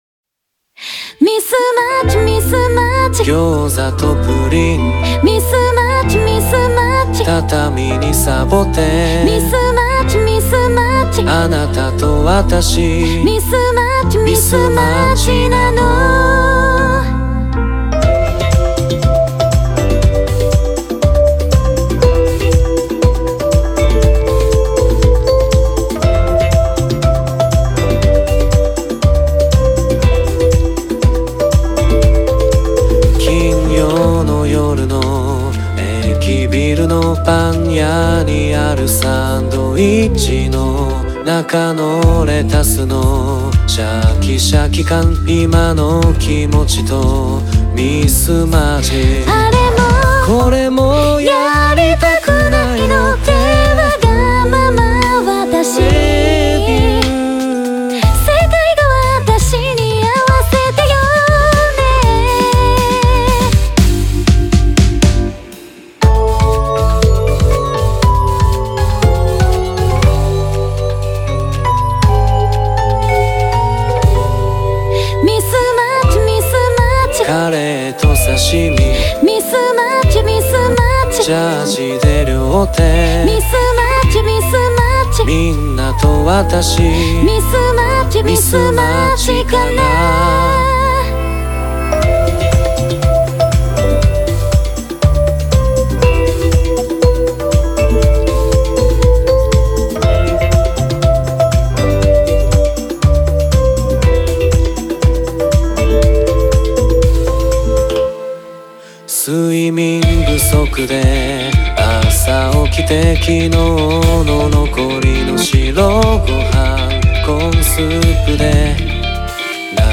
(Synthesizer V)